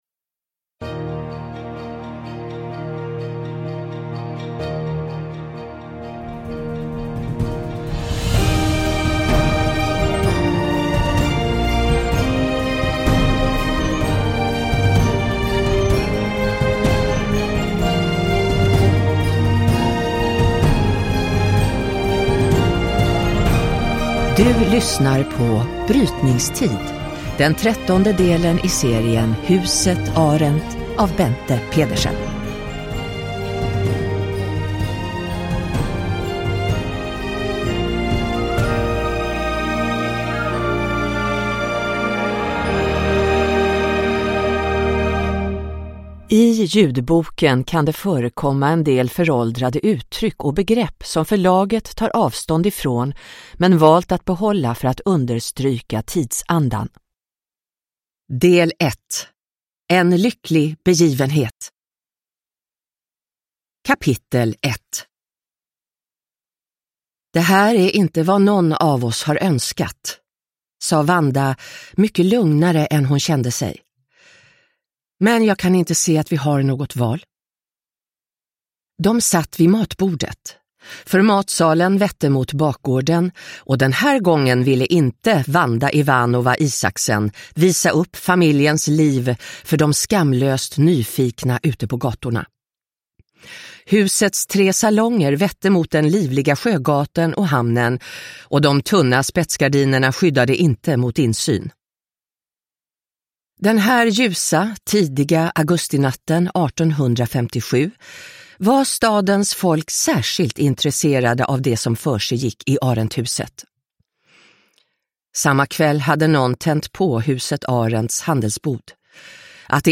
Brytningstid – Ljudbok – Laddas ner